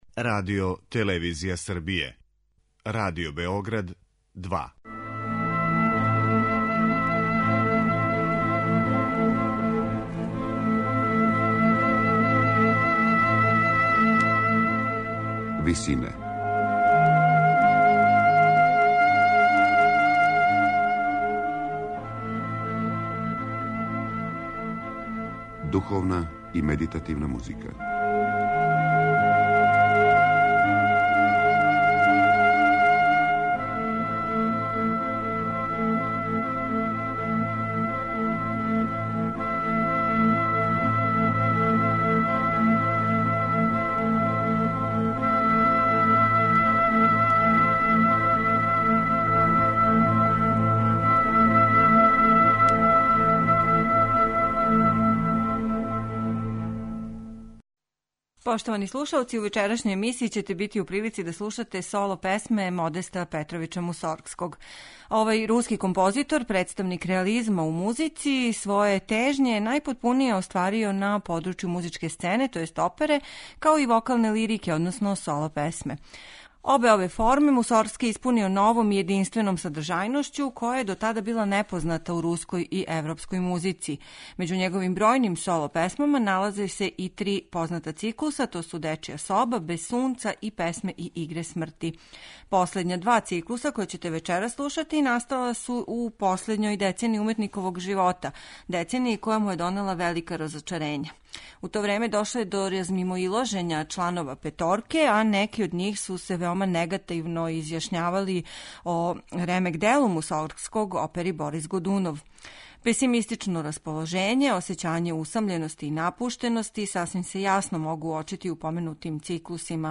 Емисија је посвећена соло песмама Модеста Мусоргског.
Композиције настале у последњој деценији његовог живота, које доносе песимистично расположење, осећање усамљености и напуштености, слушаћете у тумачењу нашег славног баса Мирослава Чангаловића.